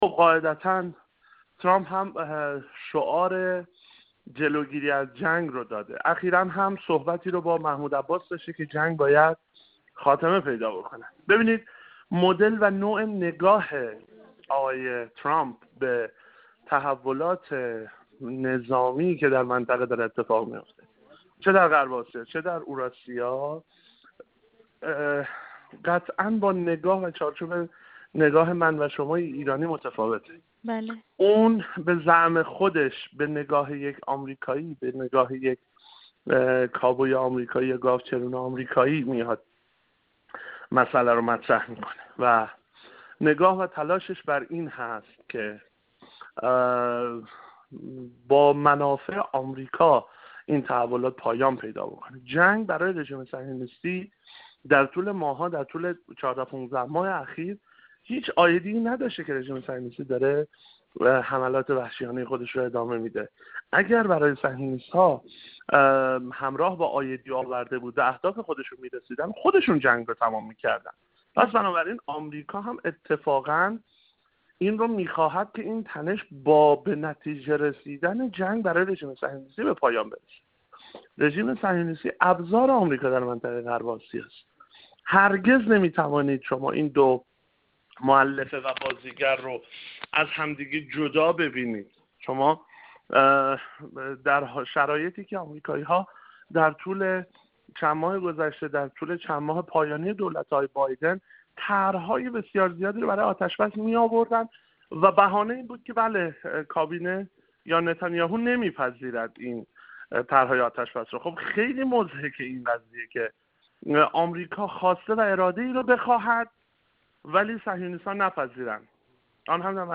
کارشناس مسائل بین‌الملل
گفت‌وگو